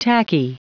Prononciation du mot tacky en anglais (fichier audio)
Prononciation du mot : tacky